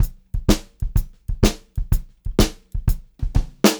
126CLBEAT1-R.wav